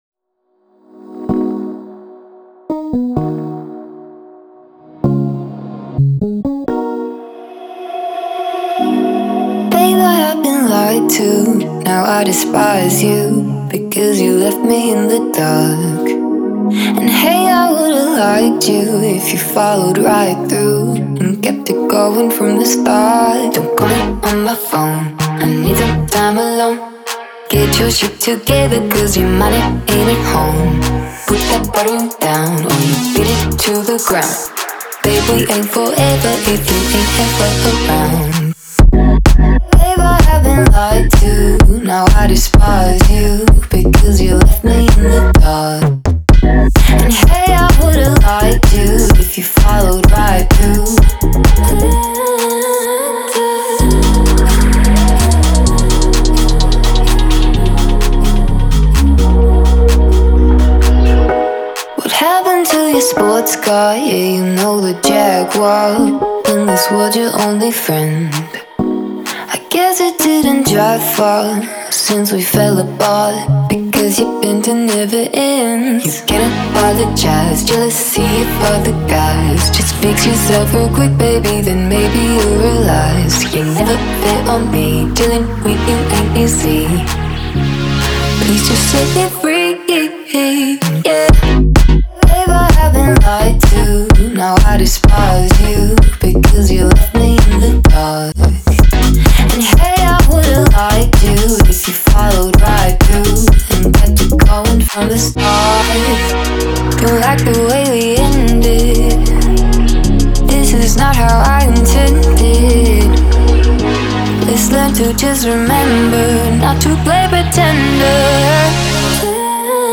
запоминающимся мелодичным вокалом
динамичными битами и синтезаторами